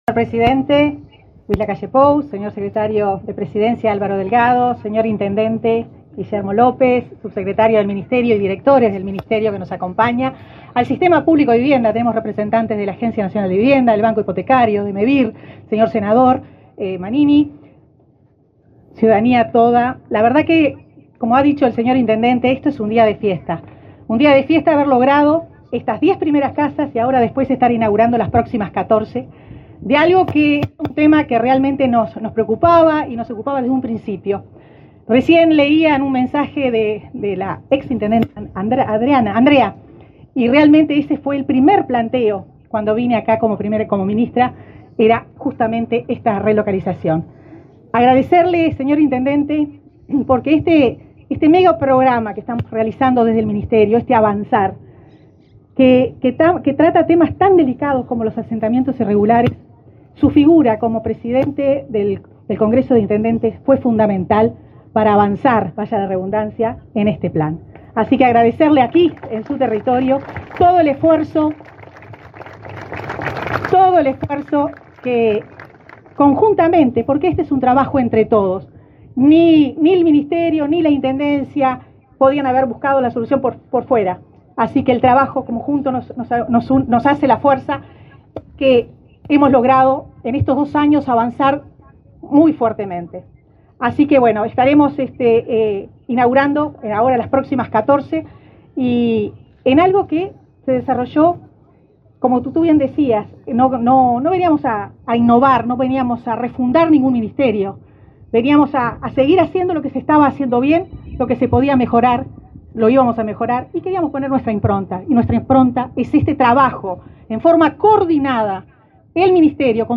Palabras de la ministra de Vivienda y Ordenamiento Territorial, Irene Moreira
La ministra Irene Moreira participó en el acto.